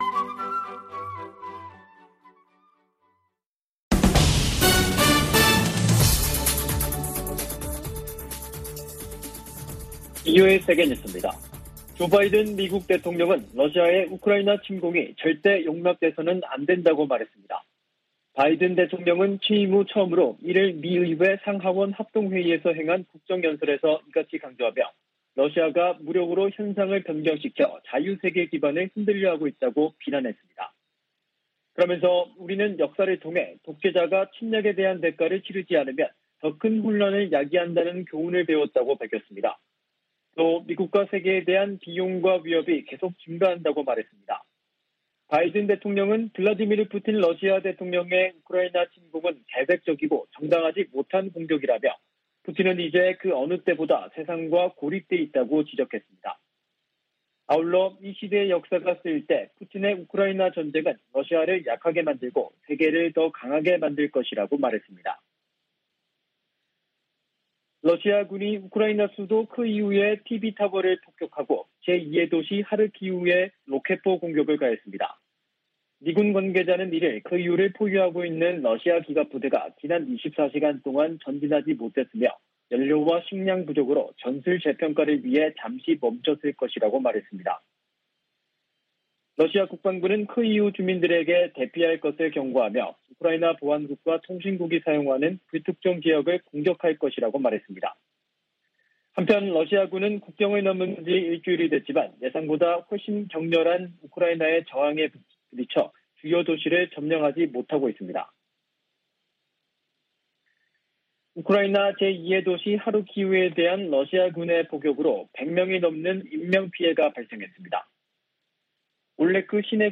VOA 한국어 간판 뉴스 프로그램 '뉴스 투데이', 2022년 3월 2일 3부 방송입니다. 조 바이든 미국 대통령이 취임 후 첫 국정연설에서 러시아의 우크라이나 침공을 강력 비판했습니다.